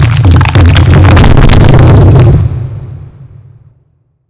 stonefall3.wav